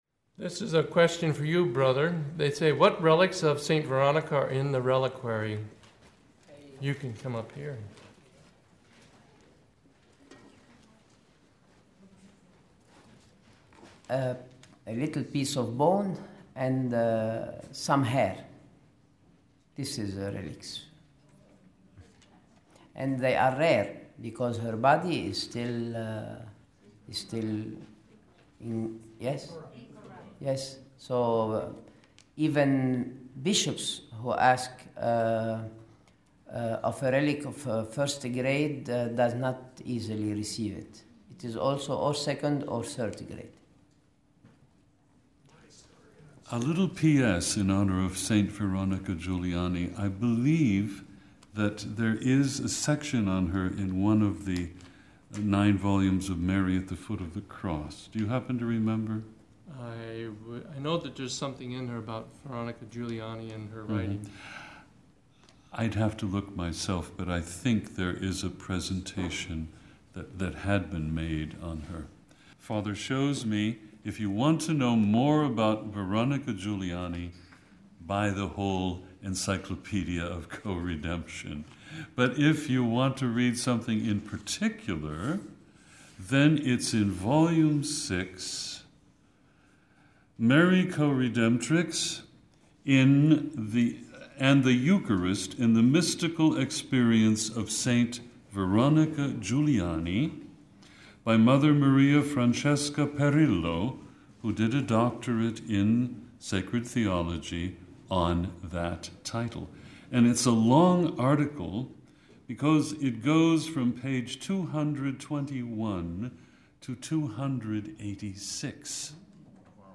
Video – Second Q&A – Coredemptrix: Why the Dogma?